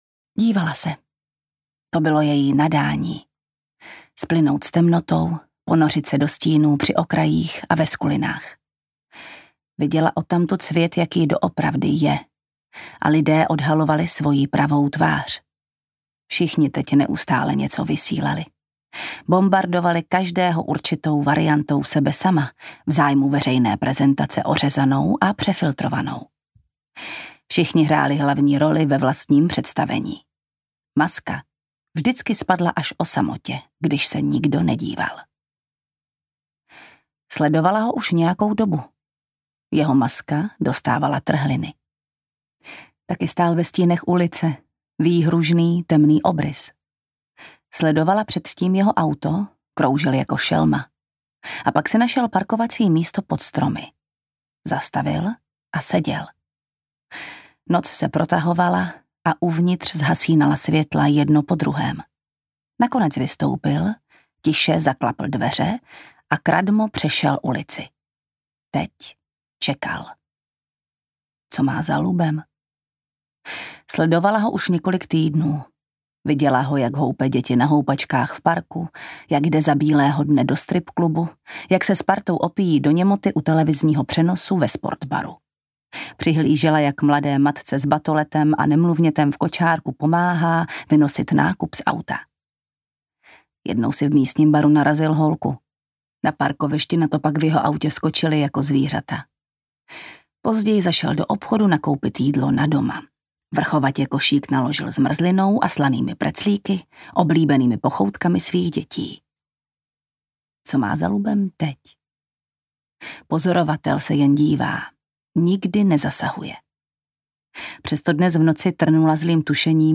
Cizinky ve vlaku audiokniha
Ukázka z knihy
• InterpretJana Stryková